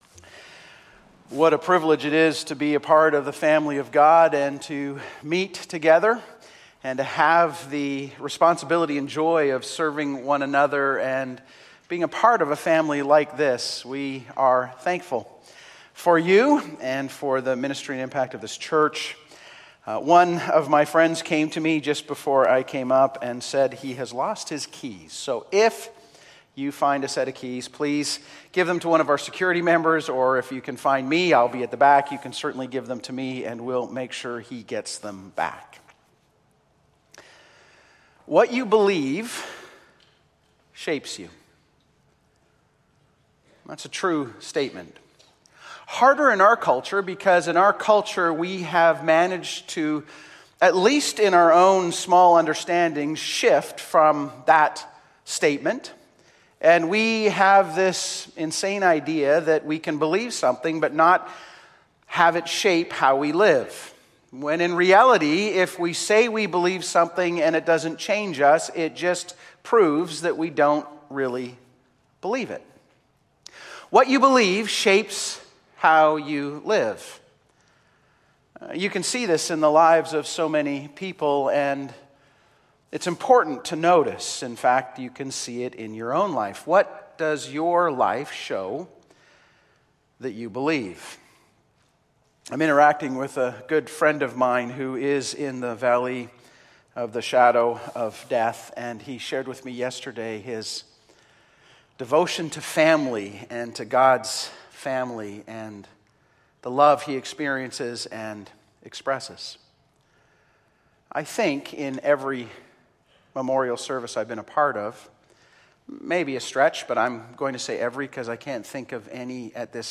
Audio Sermons from Cloverdale Baptist Church, located in Surrey, British Columbia.